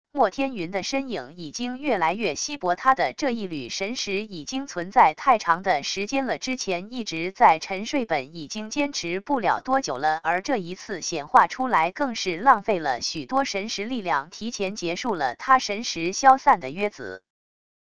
生成语音 下载WAV